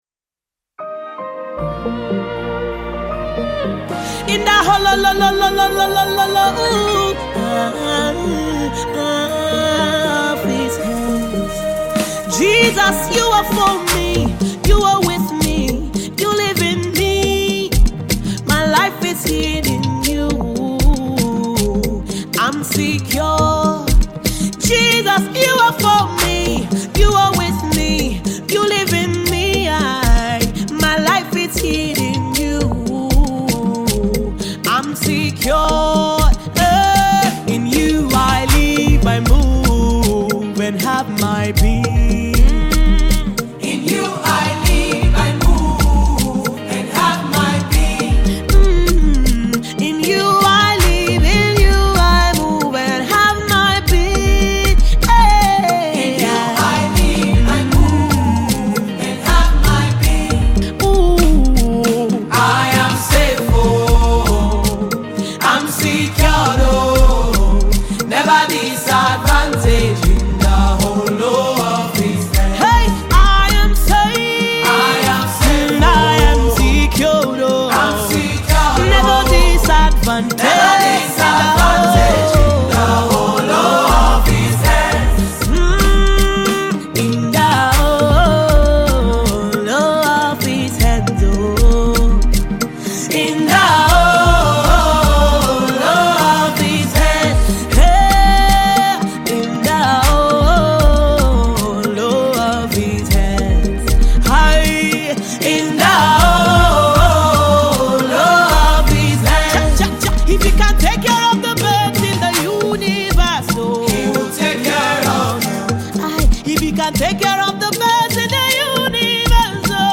uplifting melodies